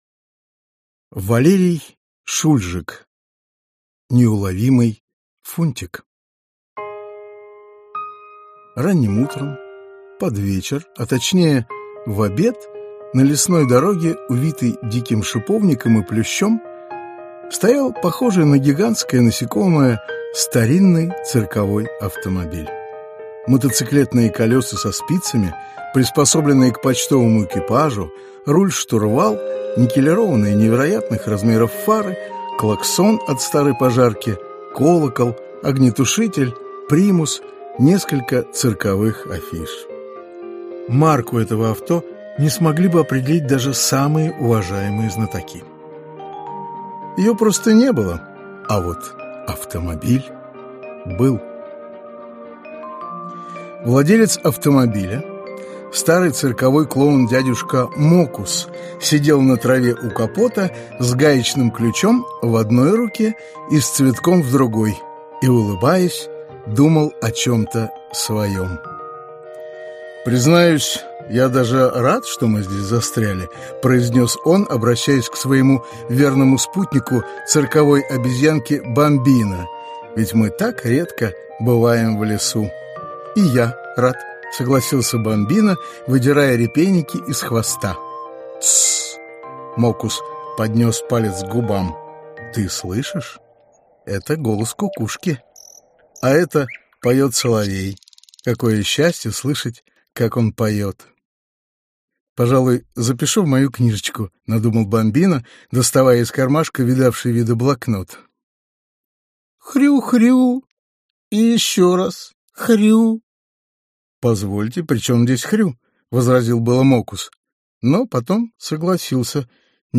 Аудиокнига Детям от 3 до 8 лет. Неуловимый Фунтик | Библиотека аудиокниг